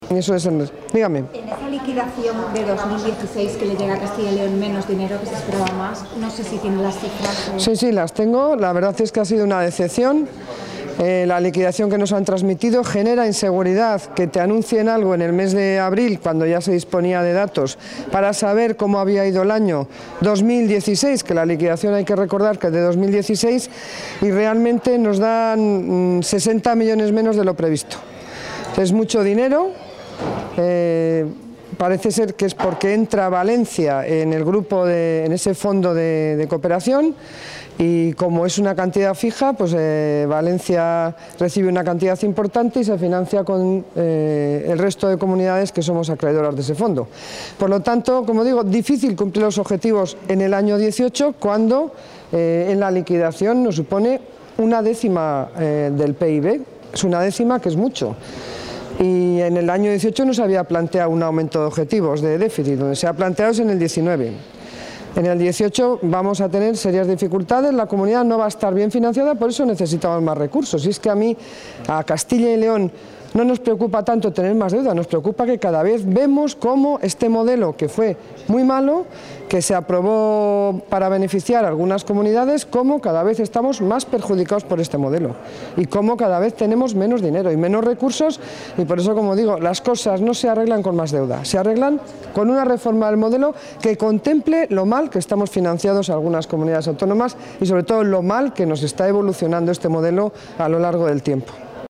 Declaraciones de la consejera de Economía y Hacienda después del Consejo de Política Fiscal y Financiera